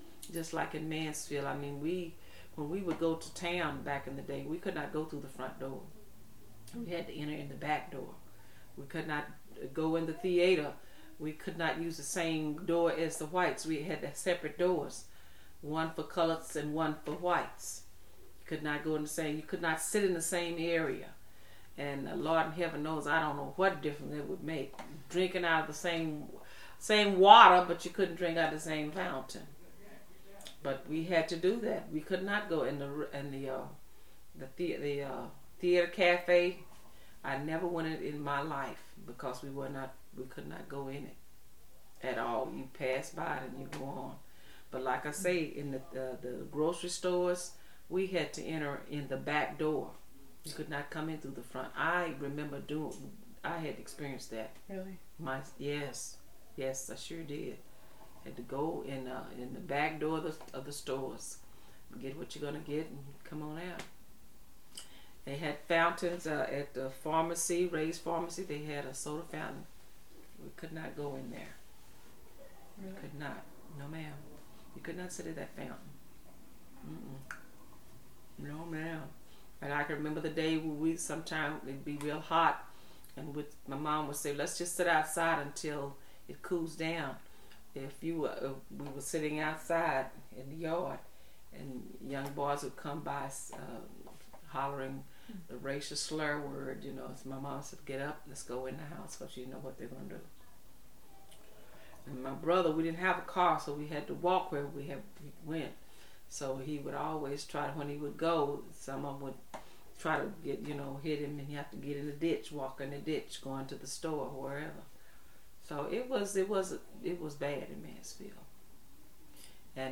interviewer
interviewee
Oral Histories